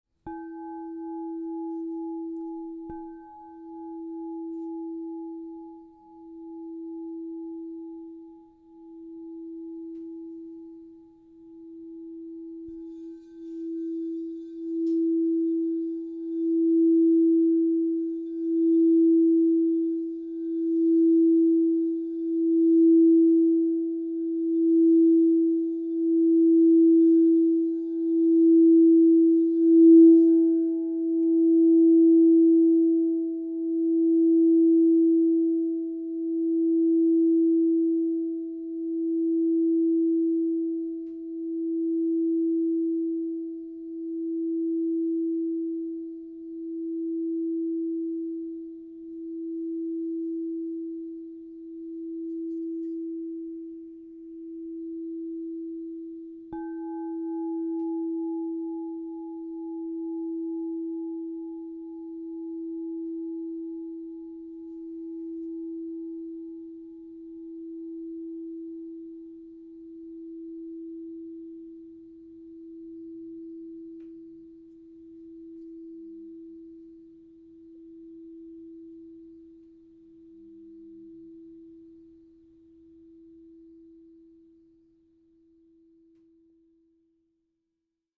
Transform your journey with this Aqua Aura Gold 8" Crystal Tones® True Tone alchemy singing bowl, in the key of E 0.